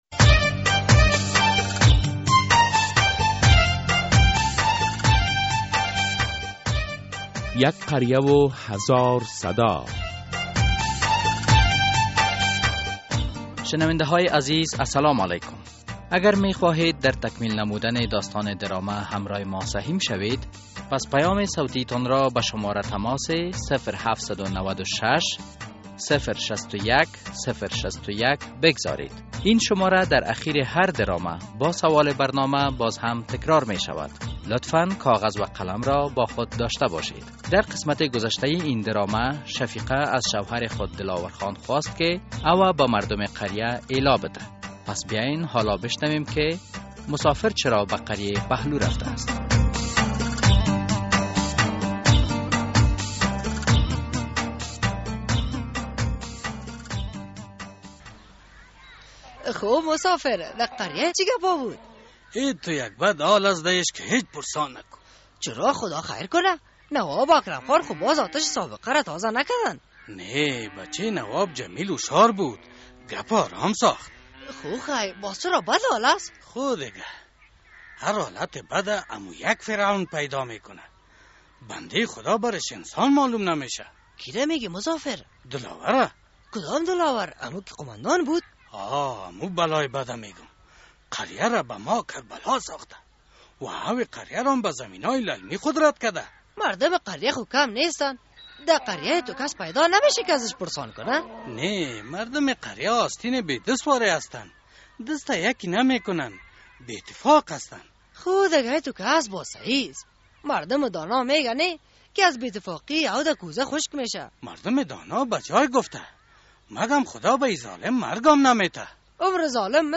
درامهء «یک قریه و هزار صدا» هر هفته به روز های دوشنبه ساعت 05:30 عصر بعد از نشر فشرده خبر ها از رادیو آزادی پخش می شود.